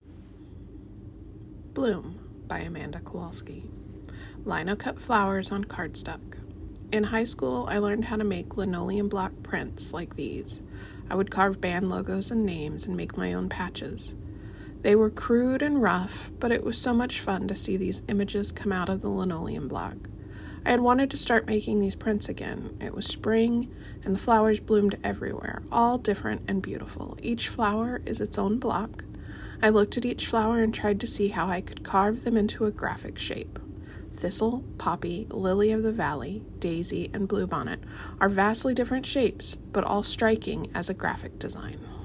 Audio recording of artist statement